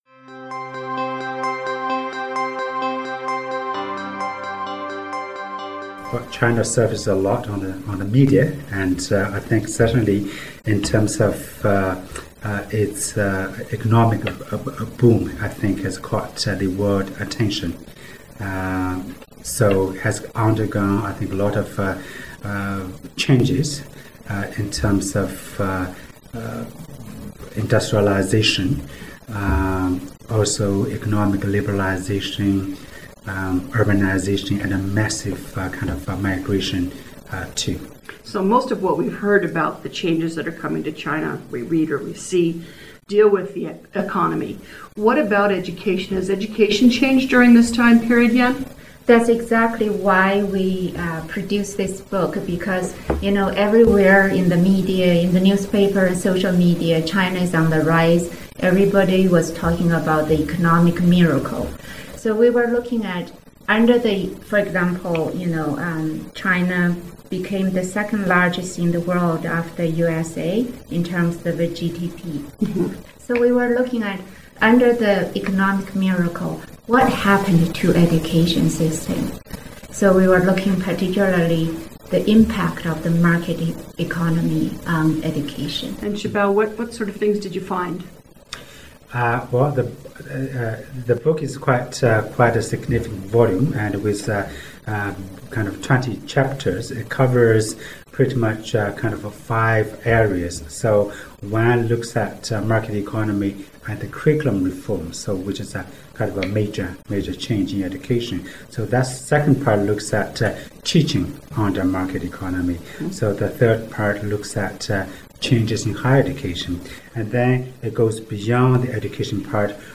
Werklund professors discuss the effects of economic market reforms on education